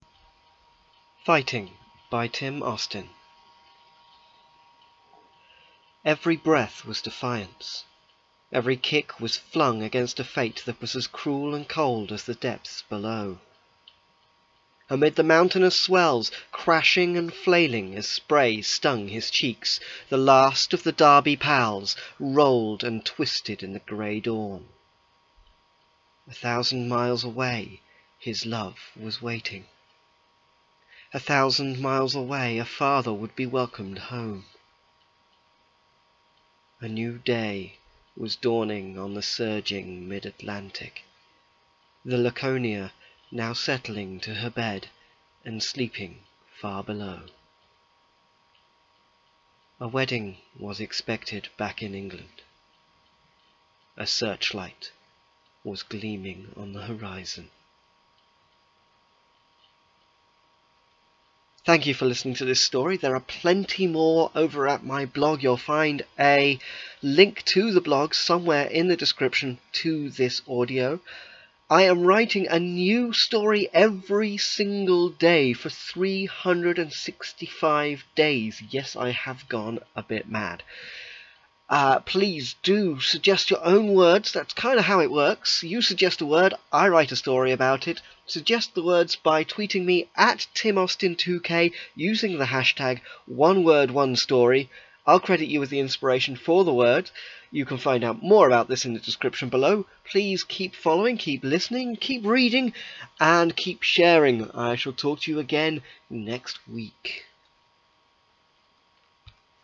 A reading of "Fighting"